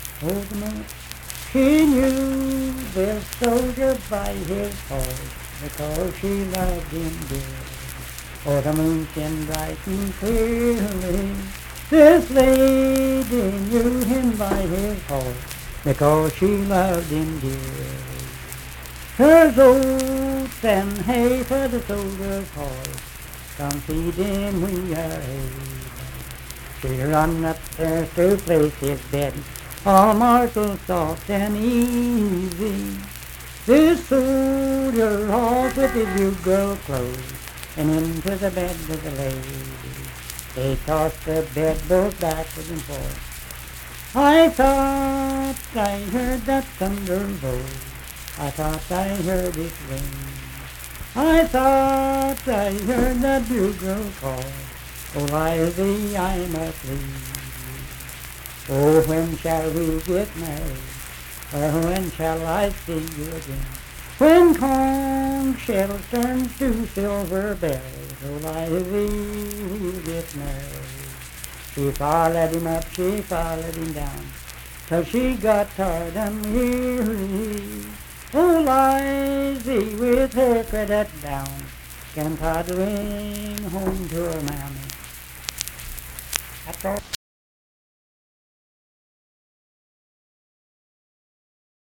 Unaccompanied vocal music performance
Verse-refrain 7(2-4).
Voice (sung)